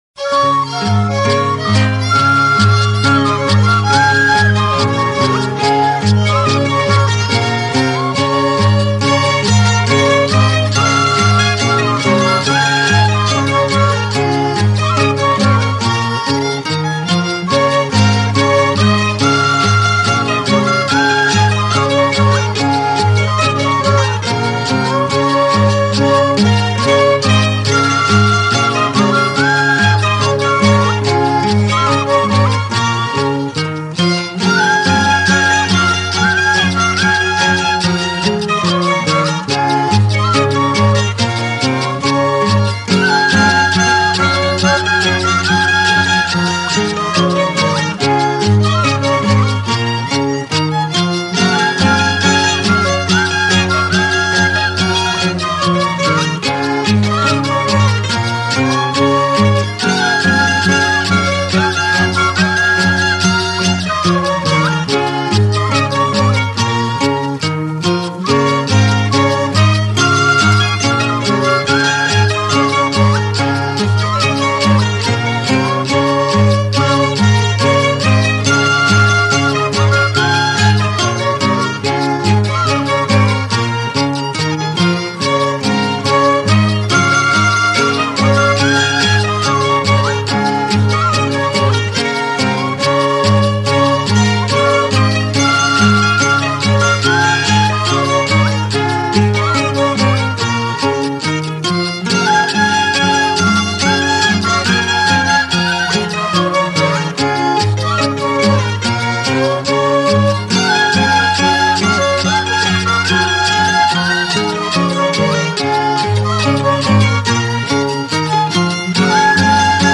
DANZAS COSTUMBRISTAS